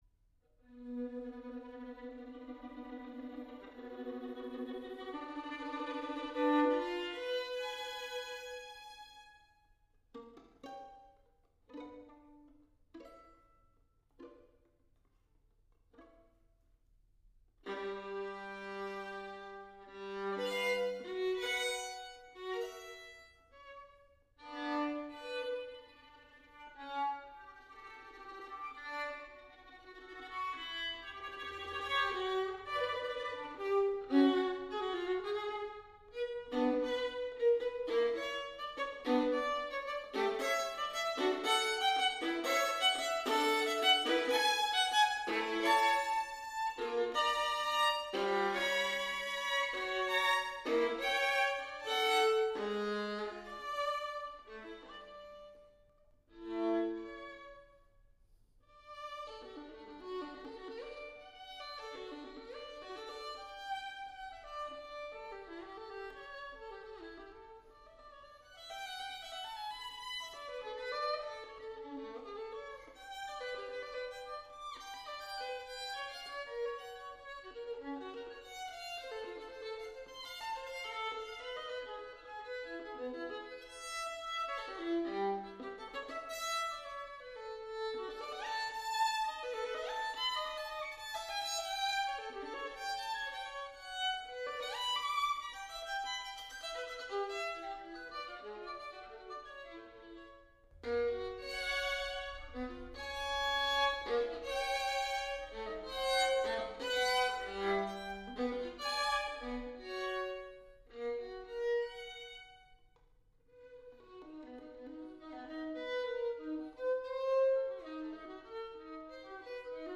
St John the Baptist Church, Aldbury